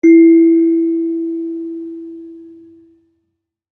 kalimba1_circleskin-E3-pp.wav